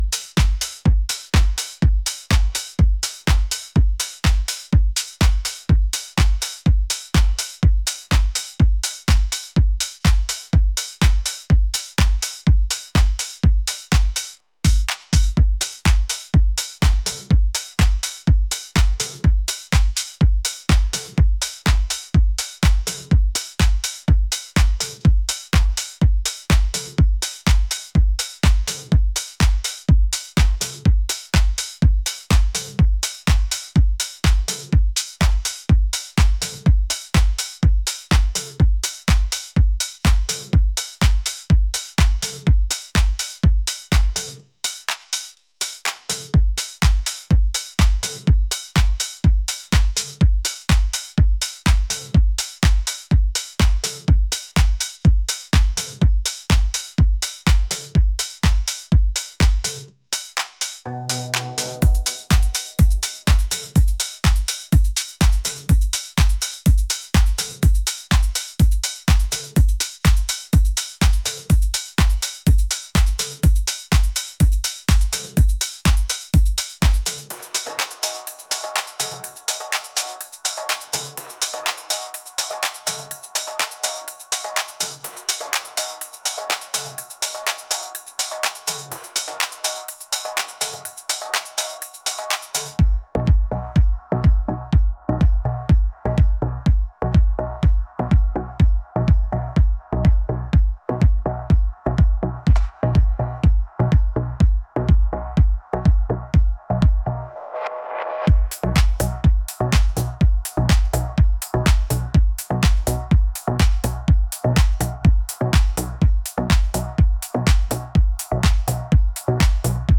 funky